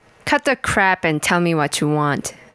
Human Female, Age 22